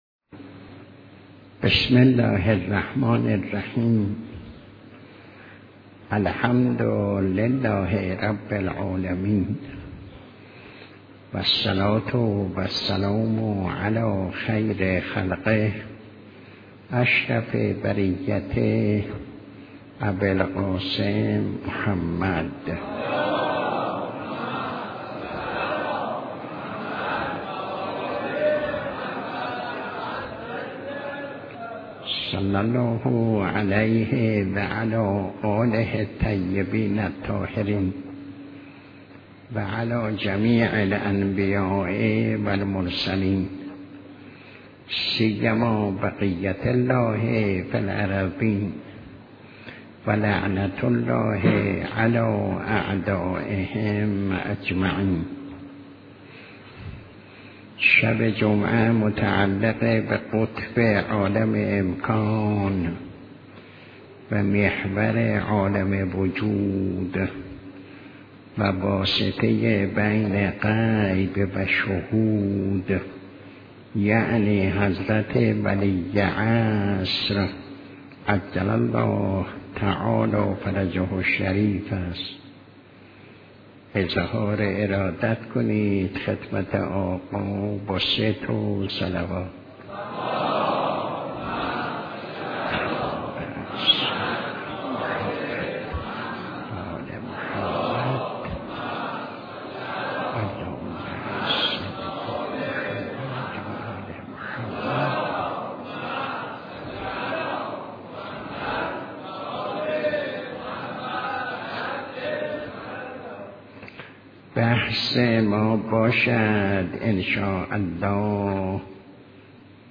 درس اخلاق - انتظار فرج 20 / 4 / 92